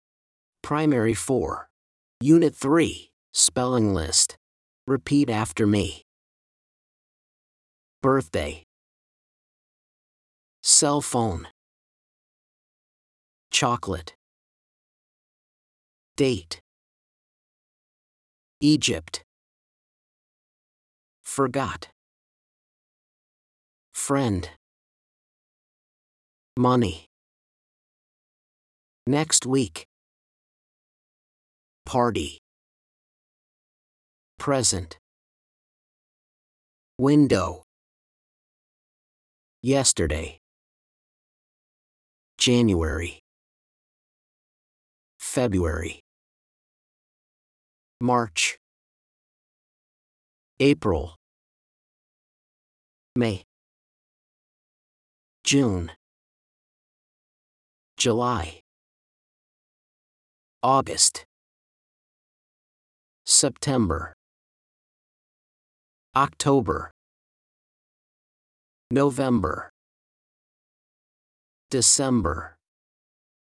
SPELLING LIST FOR UNIT 3
blankListen to the teacher: